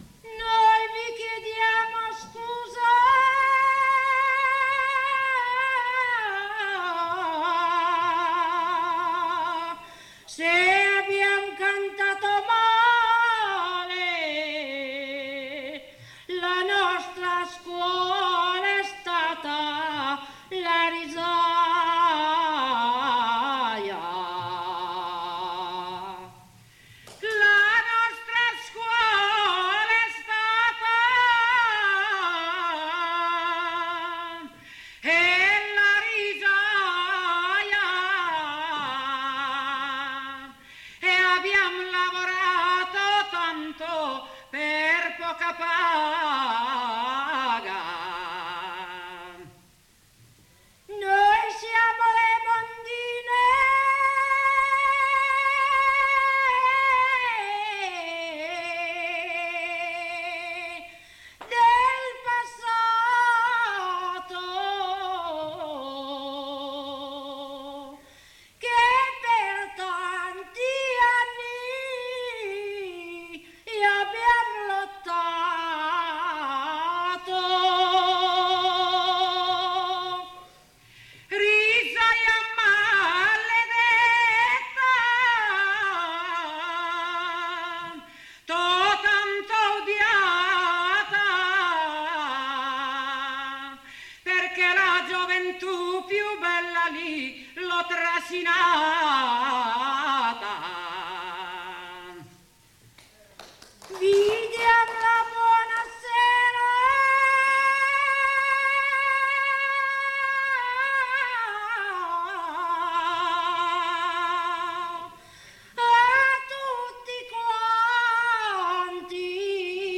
Registrazioni dal vivo e in studio, 1982 circa